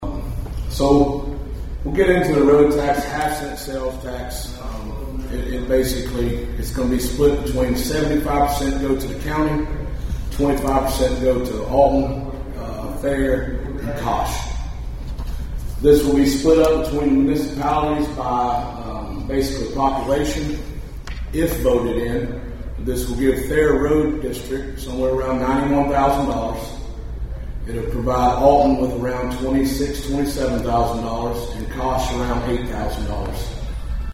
A Countywide Town Hall Meeting was held last night at the Thayer High School.
Then, Oregon County Northern Commissioner Jake Parker talked about the Capital improvement tax for roads and bridges in the county.